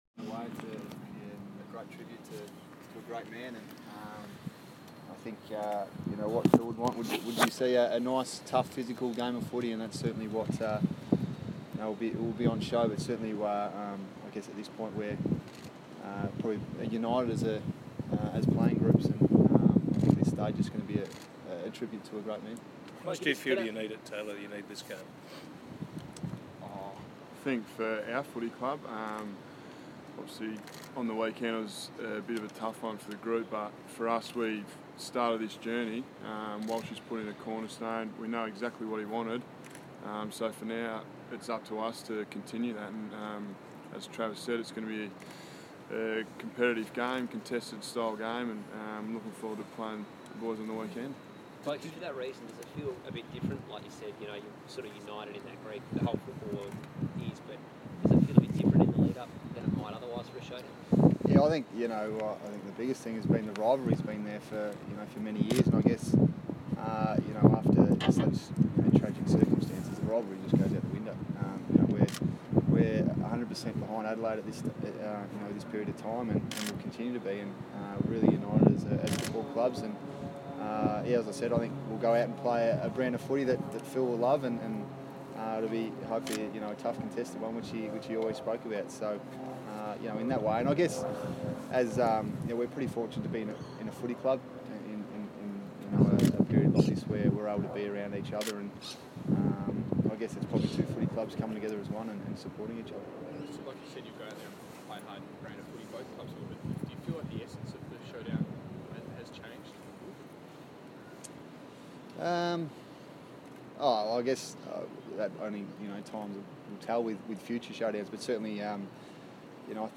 Showdown joint captain's press conference
Travis Boak and Tex Walker talk to media ahead of this Sunday's Showdown.